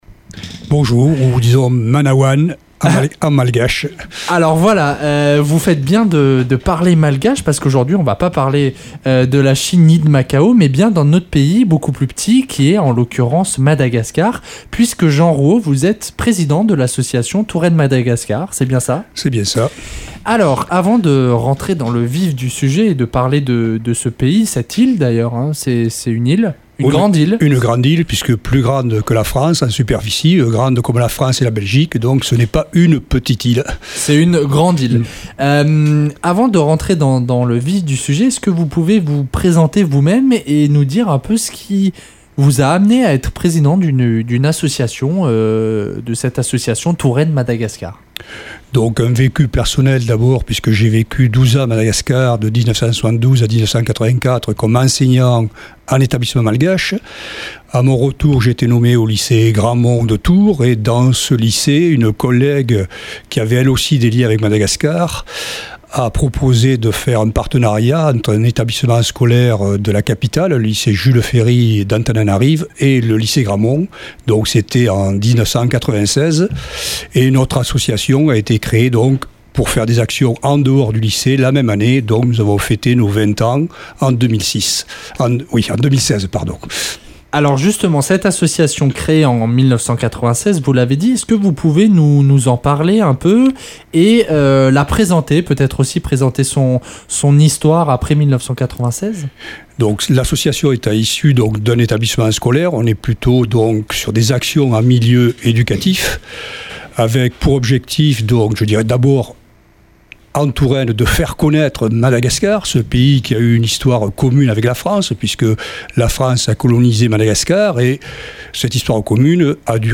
Touraine Madagascar | Interview sur Radio Campus Tours